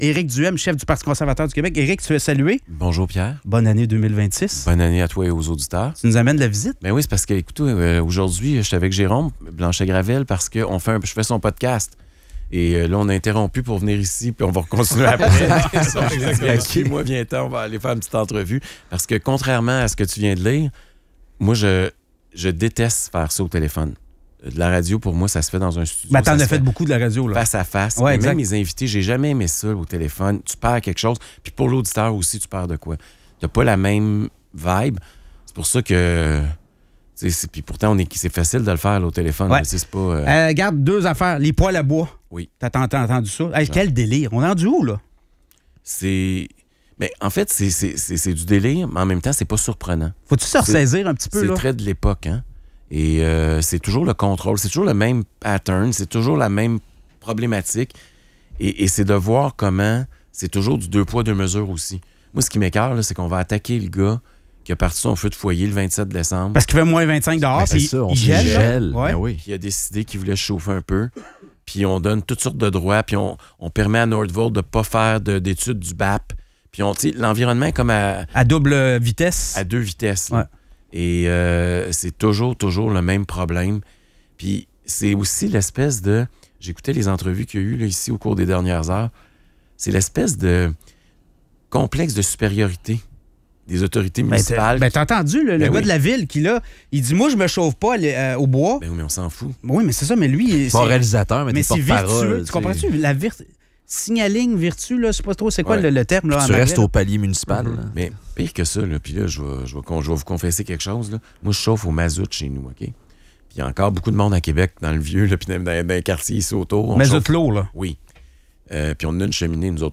En studio, le chef du Parti conservateur du Québec, Éric Duhaime.